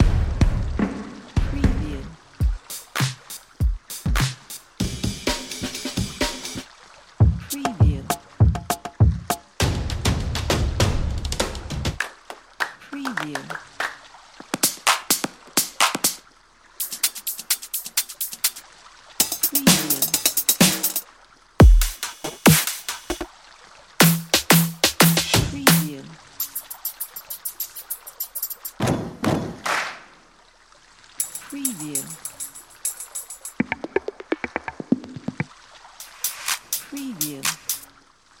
ریتم های درام کیت
ریتم های درام کیت | دانلود لوپ درام | دانلود پک پاساژ
demo-loop-drumkit.mp3